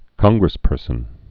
(kŏnggrĭs-pûrsən)